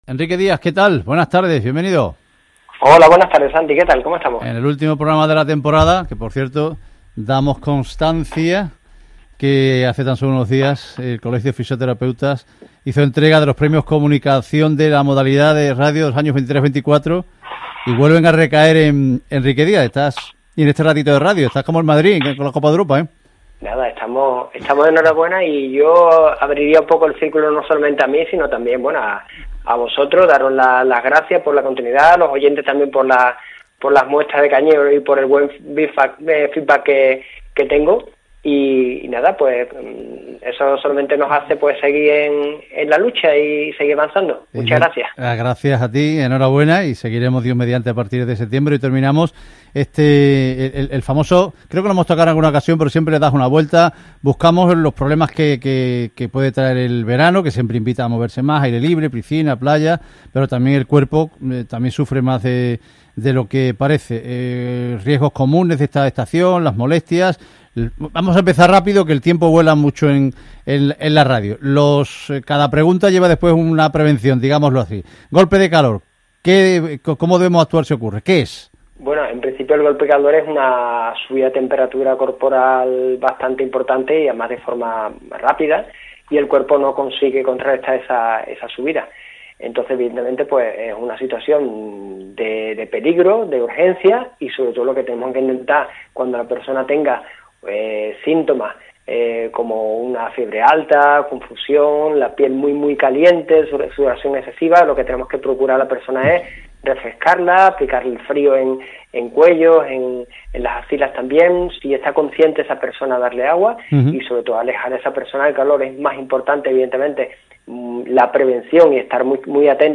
Programa emitido en la cadena SER en Ser Deportivos Andalucía el 29 de junio de 2025